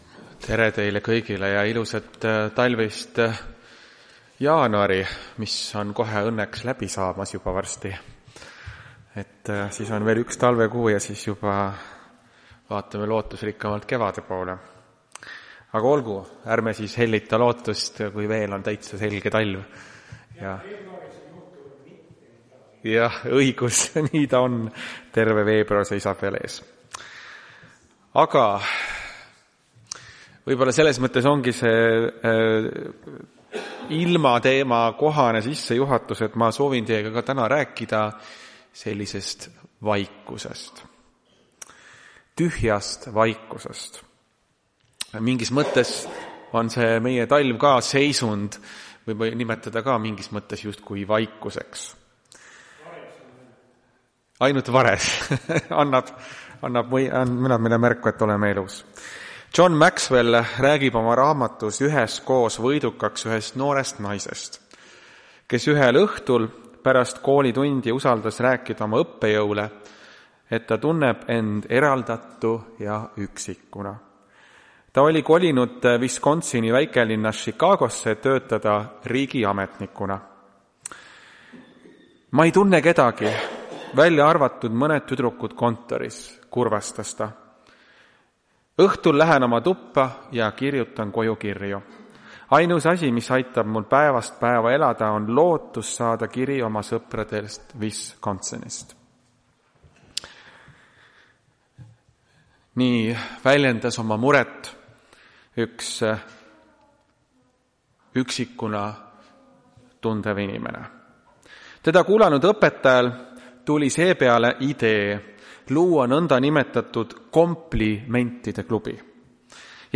Tartu adventkoguduse 24.01.2026 teenistuse jutluse helisalvestis.
Jutlused